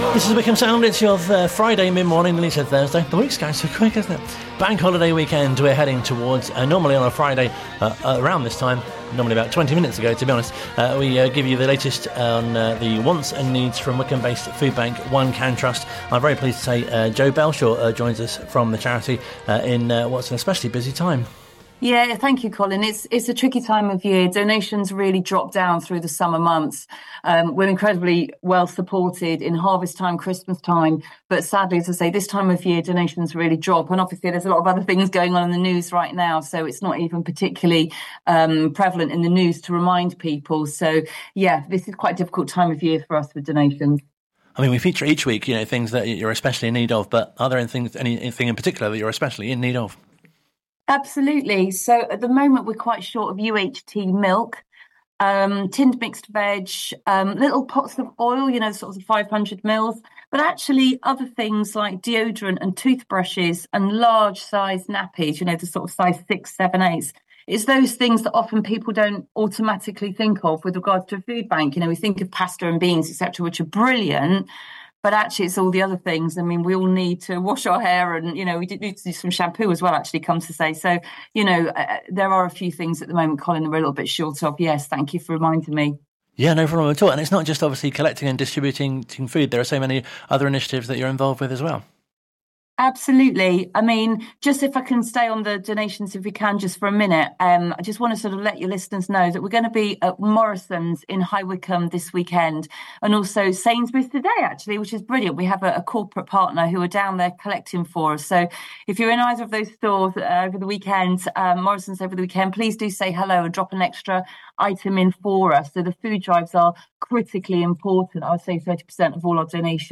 One Can Trust interview